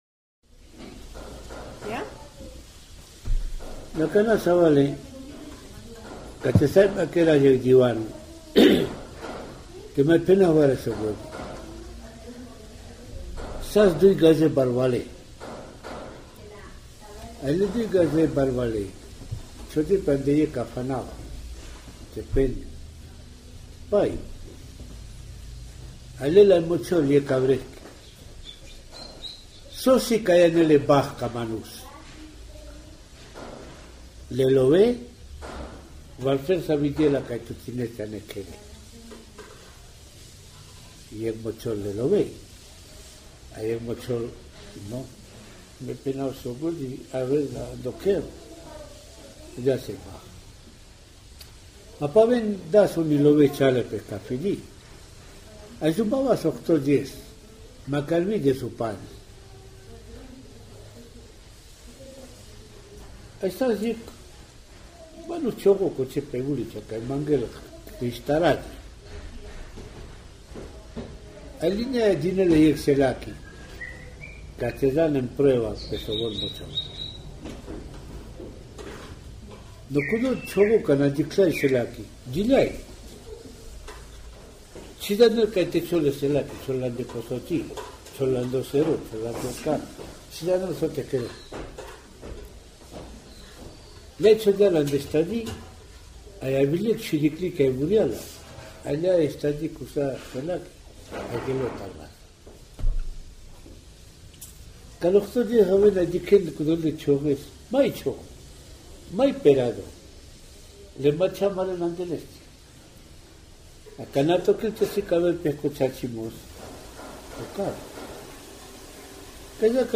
Sja kodja kêrdjol e influencija kata la Kolumbijaki španiolicko śib.
E paramiči snimisajli ande informalno konteksto kata svako-djeseko trajo. Anda kodja ašundol po snimko vi čirikljako bašamos (p.e. kataj minuta 01.15) thaj vi bućako bašamos (varekon marel etc.).
Oral Literature